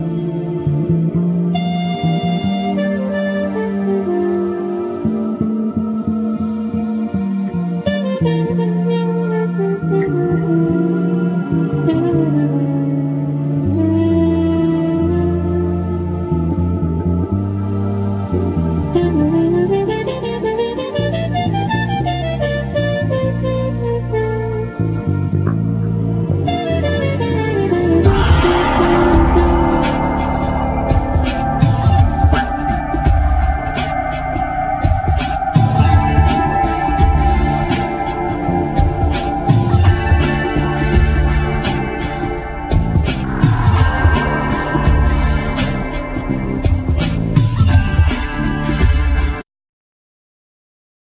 Trumpet,Flugelhorn
Bass,Keyboards,Guitar,Samples
Fender Rhodes
Drums
Vocals